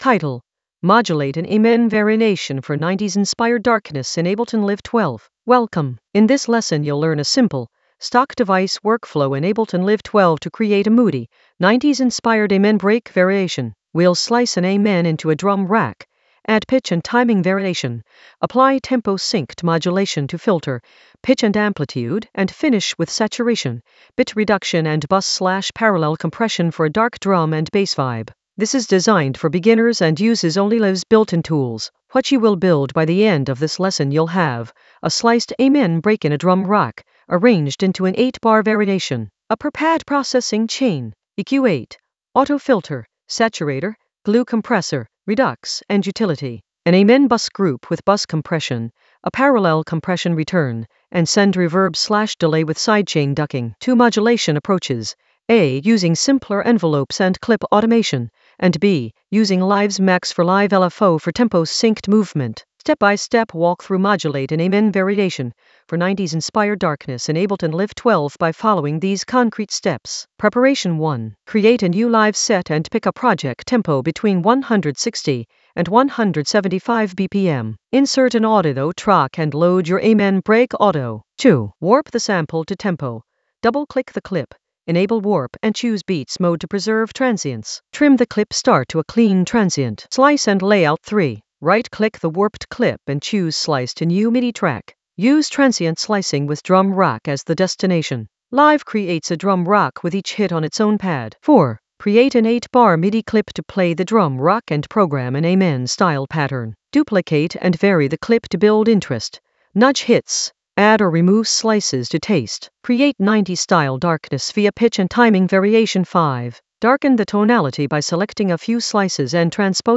An AI-generated beginner Ableton lesson focused on Modulate an amen variation for 90s-inspired darkness in Ableton Live 12 in the Mixing area of drum and bass production.
Narrated lesson audio
The voice track includes the tutorial plus extra teacher commentary.